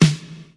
• Puffy Snare Drum Sample E Key 112.wav
Royality free steel snare drum tuned to the E note. Loudest frequency: 1034Hz
puffy-snare-drum-sample-e-key-112-igv.wav